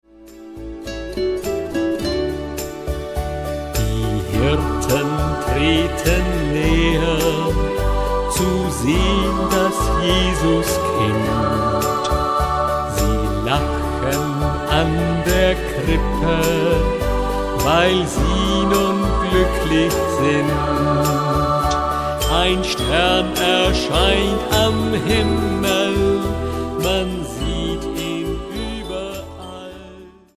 Lieder und Krippenspiele zur Advents- und Weihnachtszeit